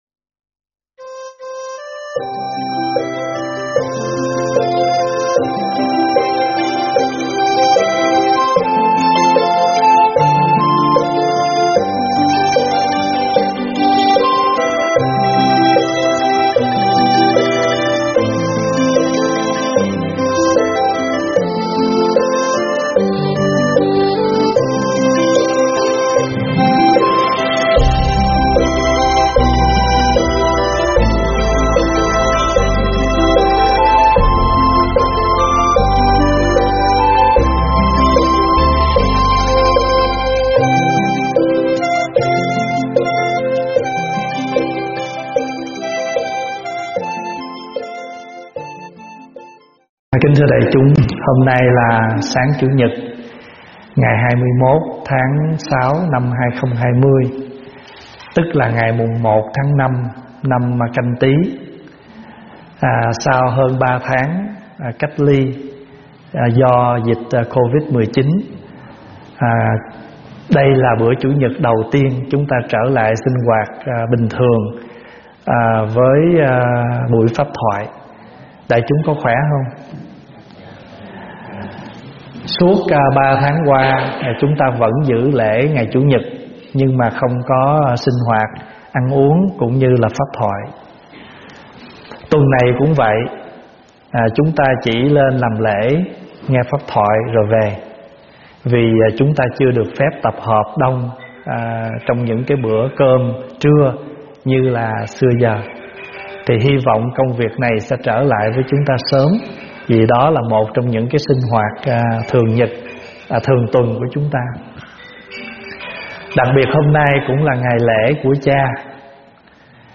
Tải mp3 thuyết pháp Huấn Luyện Tâm